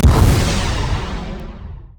sfx_rocket_launch_1.wav